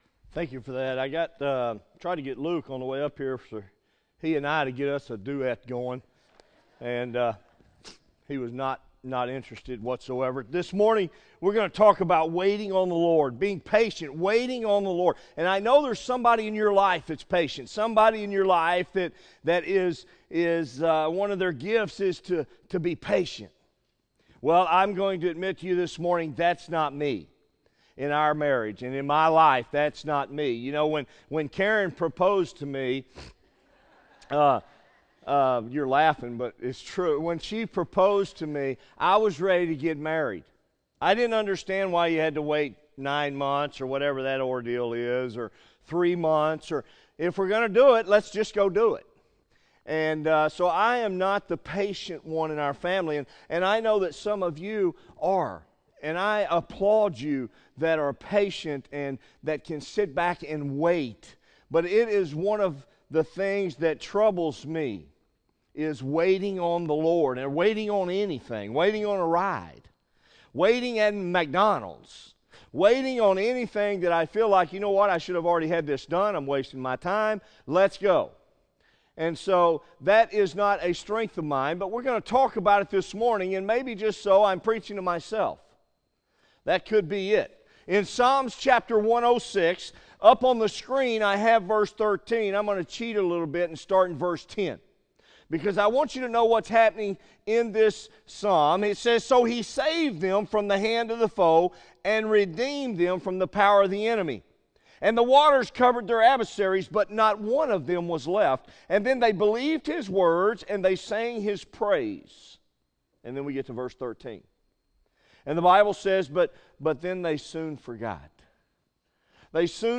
by Office Manager | Jul 17, 2017 | Bulletin, Sermons | 0 comments